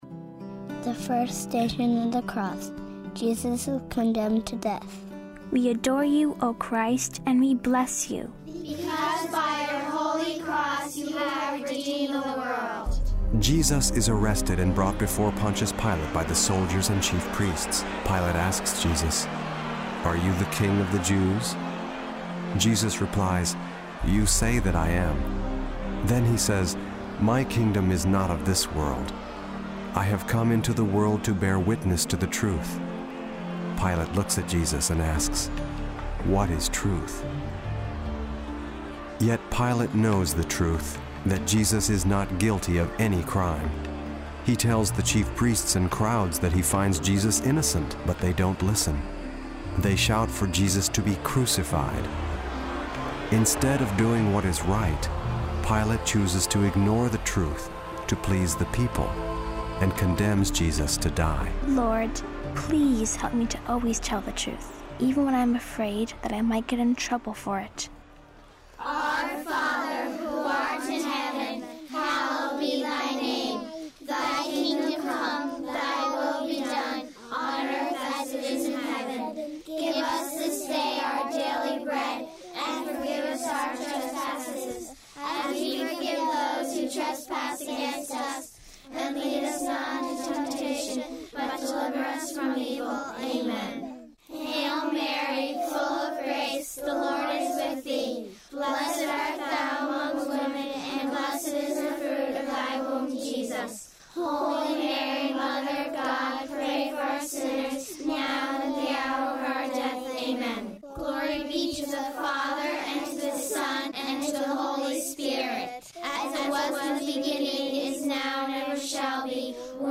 For variety in your prayers, our Stations of the Cross CD contains two complete Stations versions: the traditional version with sung Stabat Mater at each station, plus the shorter “radio-cut” version as heard on EWTN, Immaculate Heart Radio, Mediatrix, Ave Maria, and other Catholic stations nationwide!